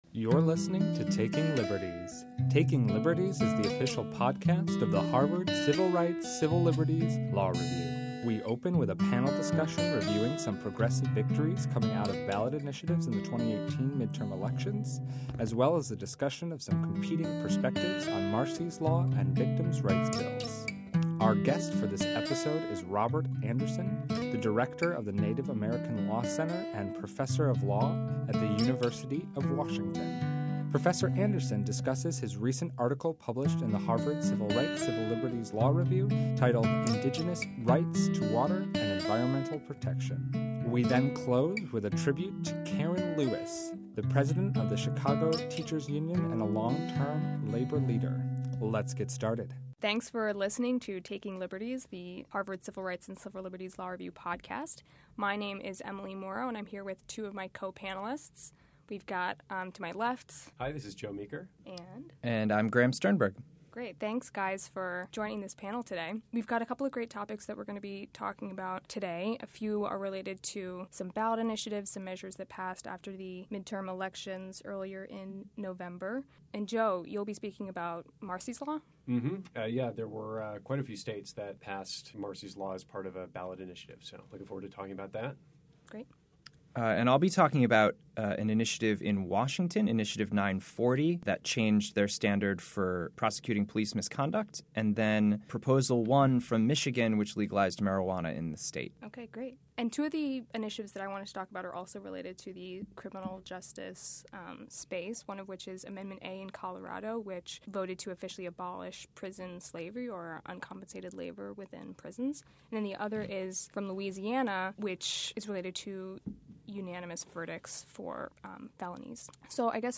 The panel reviews some progressive victories coming out of ballot initiatives in the 2018 midterm elections and has a discussion on competing perspectives on victim’s rights bills.